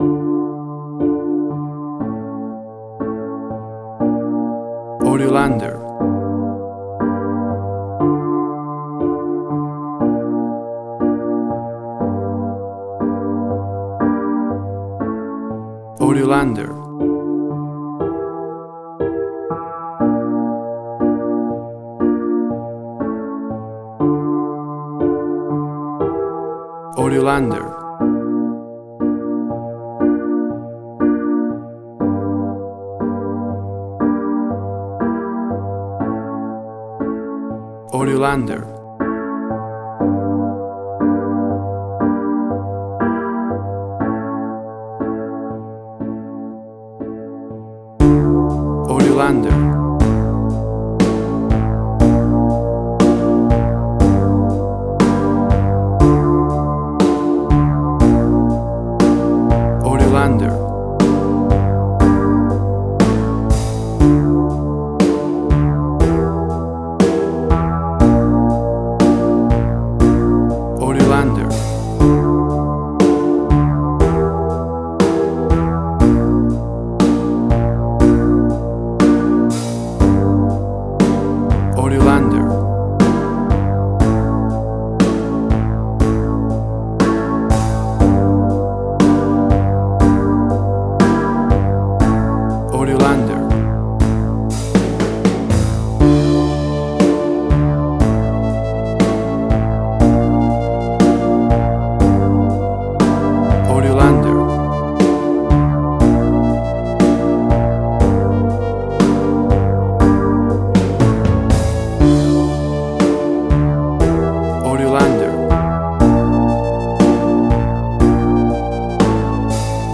Suspense, Drama, Quirky, Emotional.
Tempo (BPM): 60